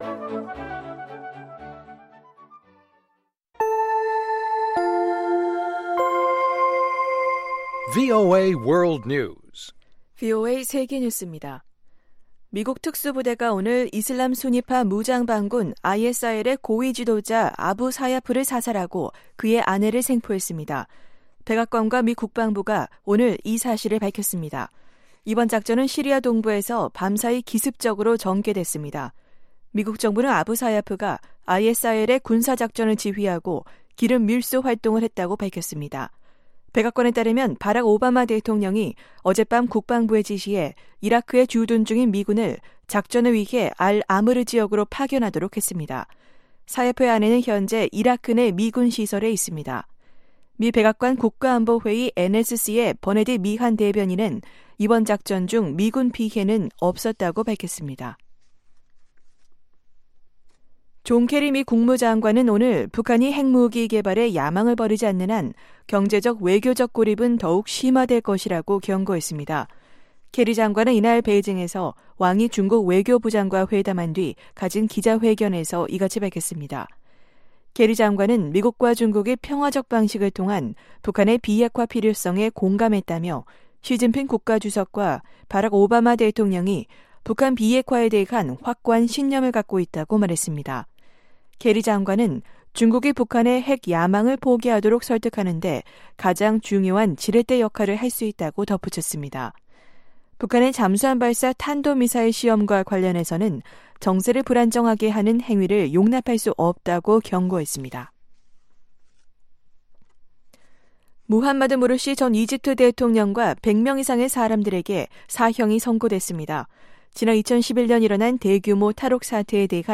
VOA 한국어 방송의 토요일 오후 프로그램 4부입니다.